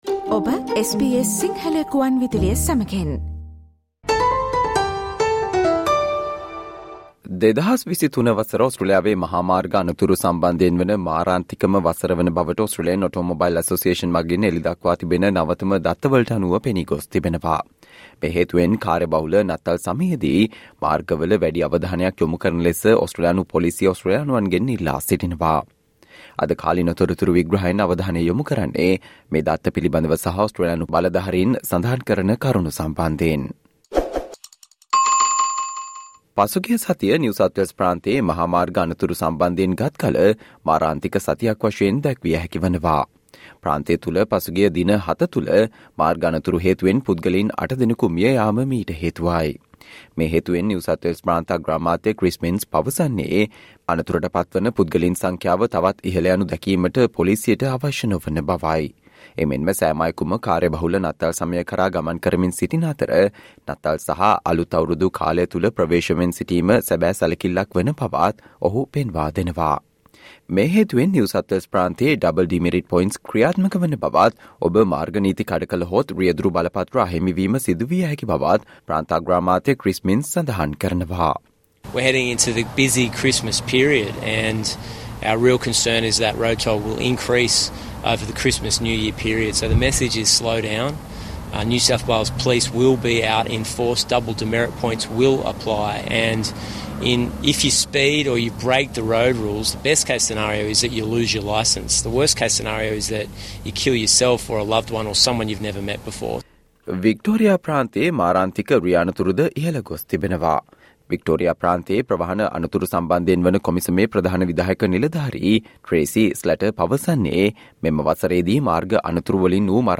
Listen to this SBS Sinhala explainer for more information.